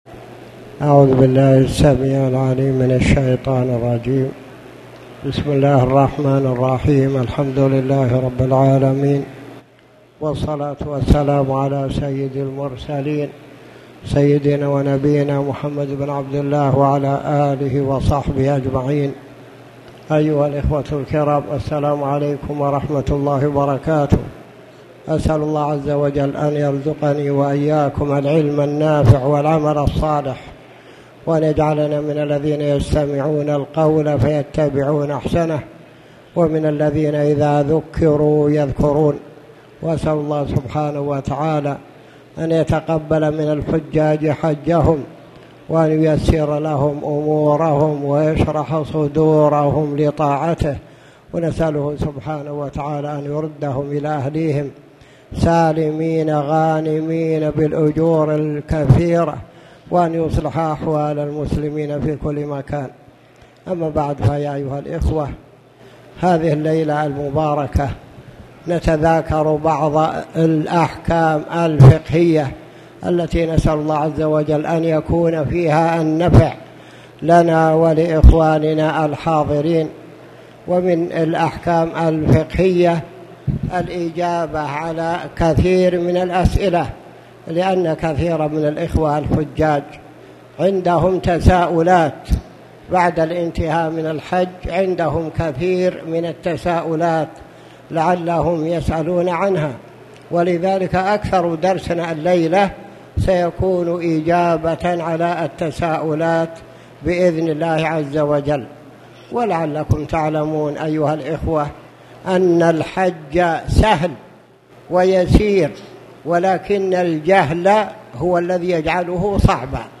الإجابة على الأسئلة
تاريخ النشر ١٥ ذو الحجة ١٤٣٨ هـ المكان: المسجد الحرام الشيخ